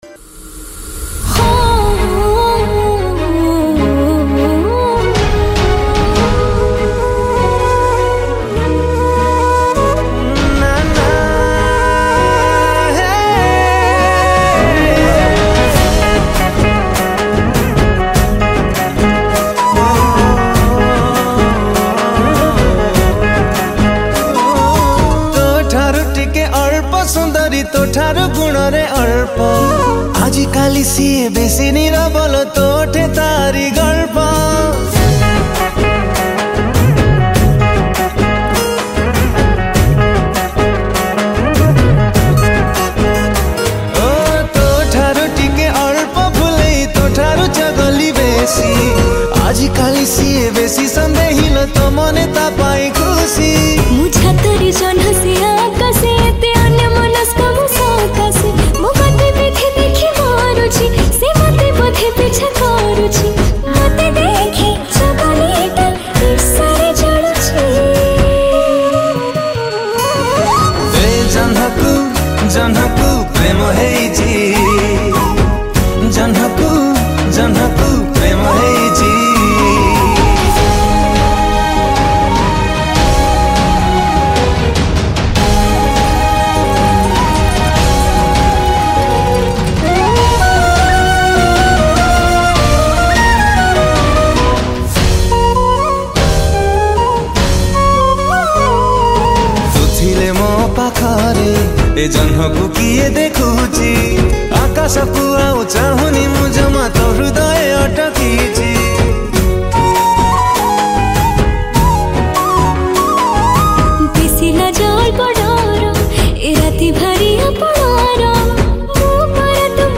Keyboard
Guitar
Flute